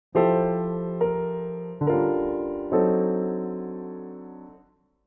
ドミナント・アプローチ
g-c♯-f-b♭というハーモナイズ
A7にとっては-13thにあたるfの音を入れてみました。